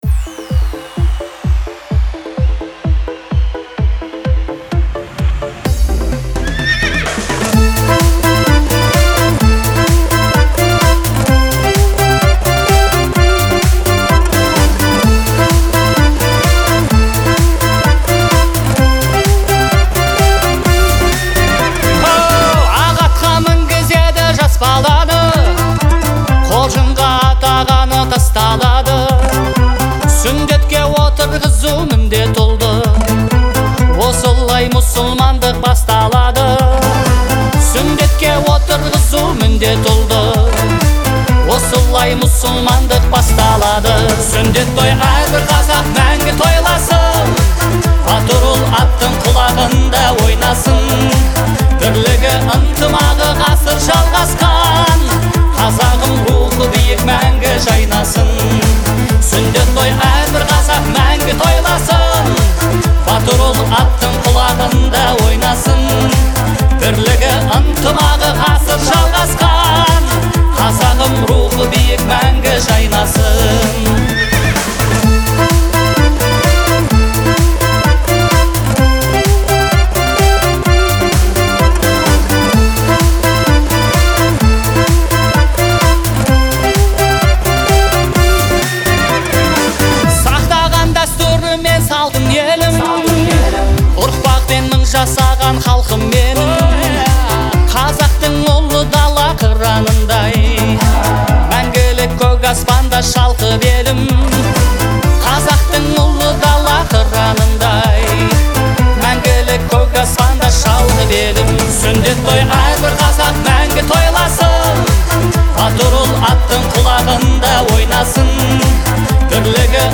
яркая и мелодичная песня